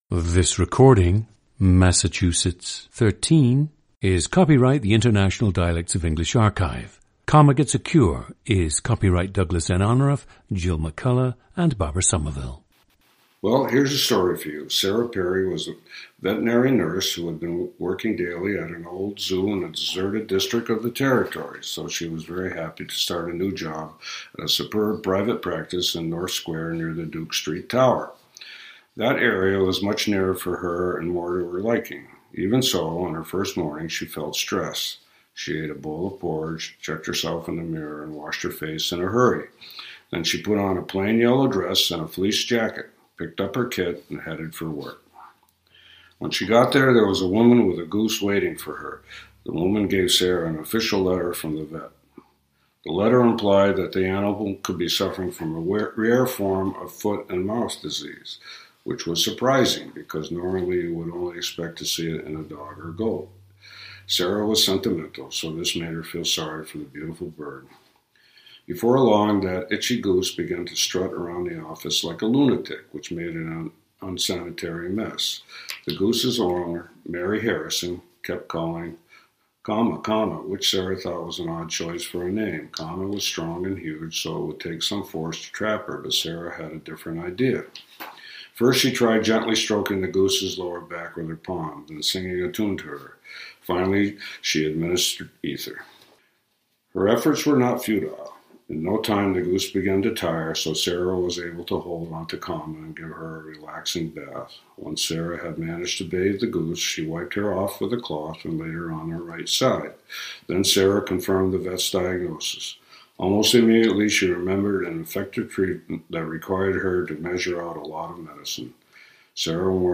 GENDER: male
The speaker has lived his entire life in western Massachusetts.
In contrast to the archetypal Boston dialect, this speaker’s dialect exhibits fairly consistent r-coloration.  In his conversational section, he notes how certain town names (Holyoke, Amherst) are pronounced by natives of the Connecticut River Valley: the hallmarks of the “local” pronunciation he cites are elided or weakened consonants, such as the medial [l] and final [k] in “Holyoke” and the medial [h] and final [t] in “Amherst.” You can hear this tendency toward weakened or elided consonants in other words as well (job, stressed, work, bird, goat).
The recordings average four minutes in length and feature both the reading of one of two standard passages, and some unscripted speech.